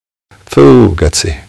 fhuu g ci Meme Sound Effect
This sound is perfect for adding humor, surprise, or dramatic timing to your content.